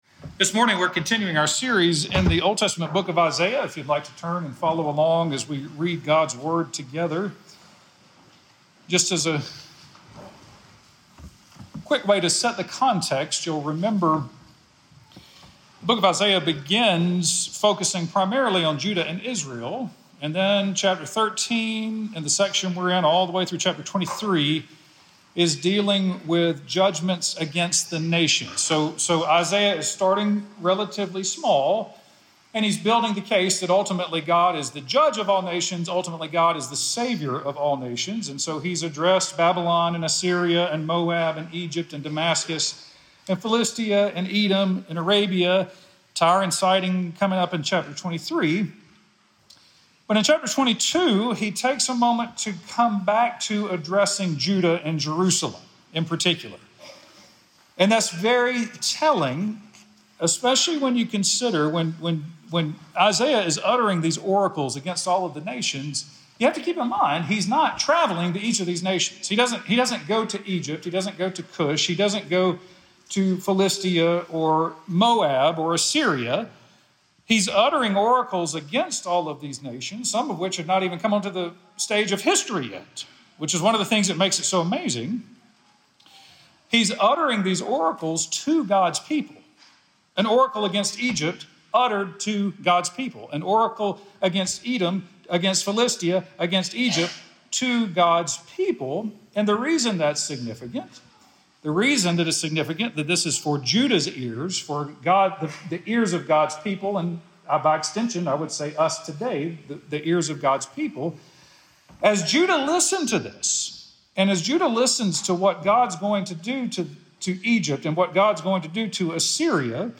Listen to An Eat and Drink Mentality sermon published on Feb 15, 2026, by Trinity Presbyterian Church in Opelika, Alabama.